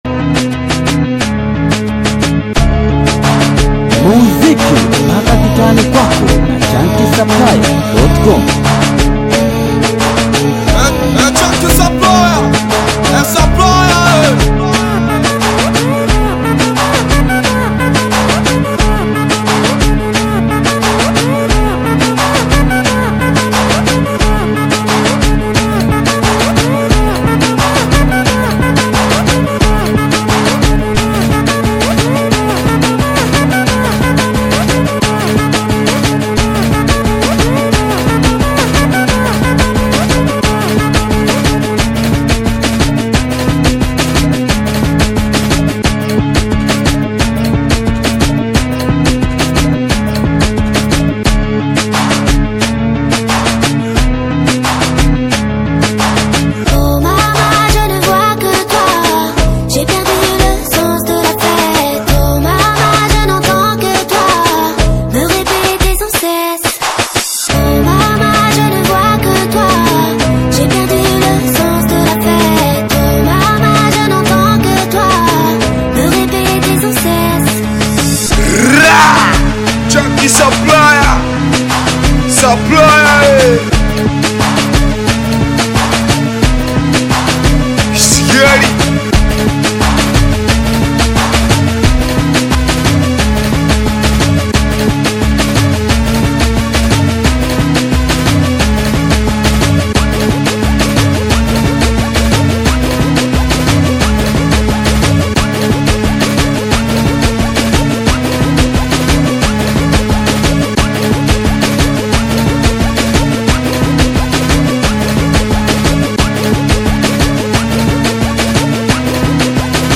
BITI SINGELI • SINGELI BEAT